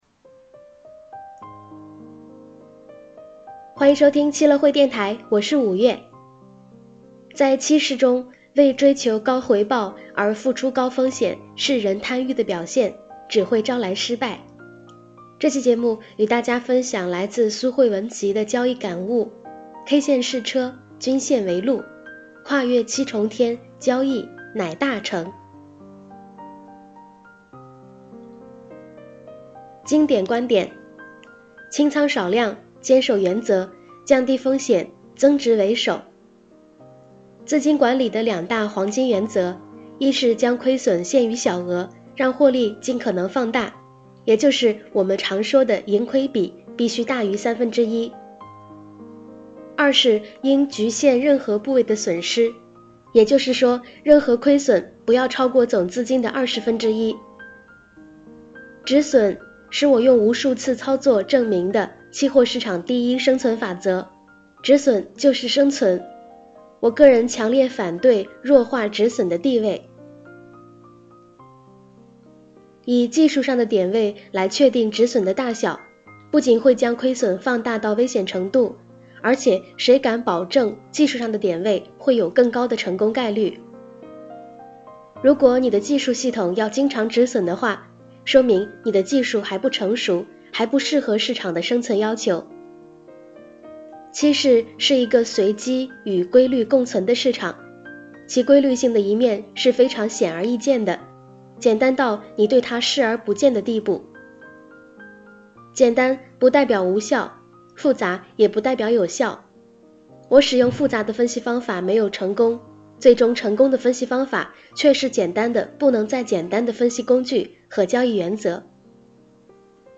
K线是车，均线为路 ！这是十年期货老手给你的忠告（一）有声版